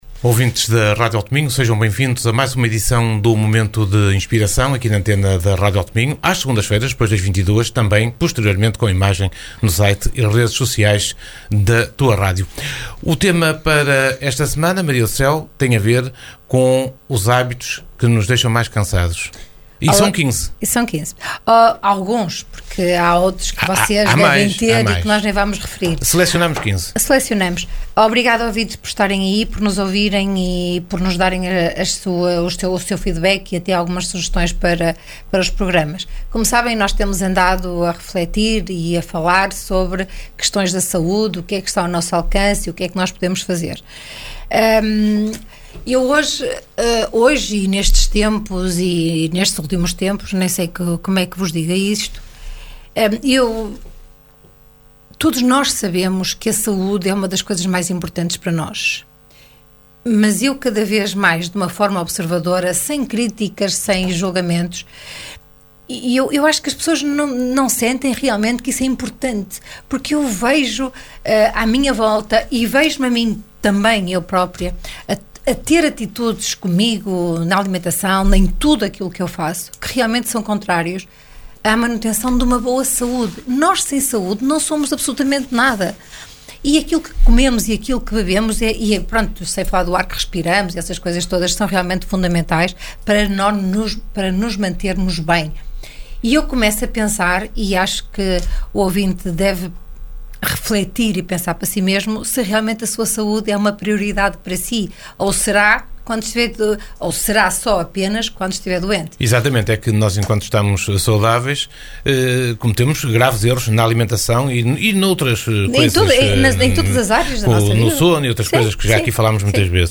Momento de Inspiração Uma conversa a dois